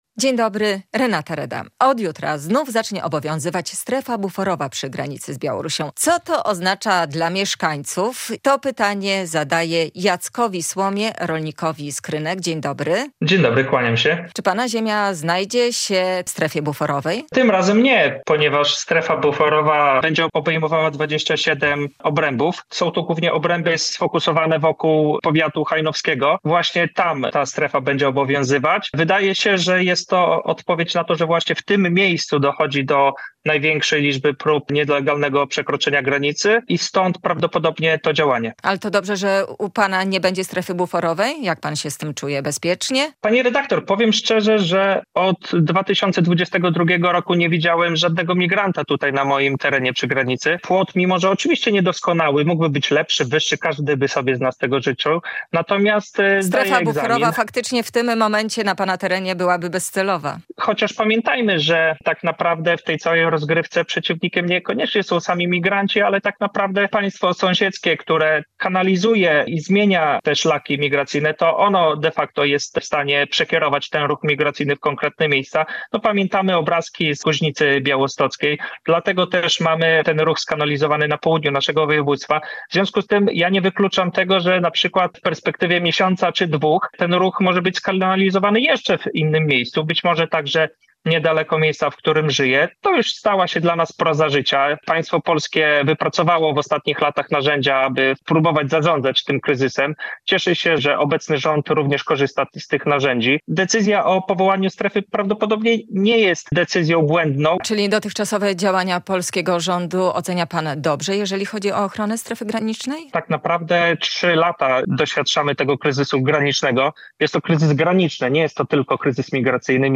rolnik z Krynek